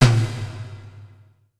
Percusión 3: timbal 2
membranófono
timbal
percusión
electrónico
golpe
sintetizador